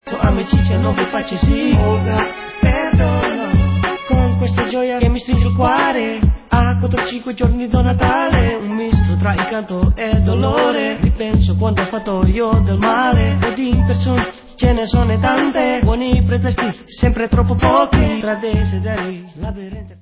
Salsa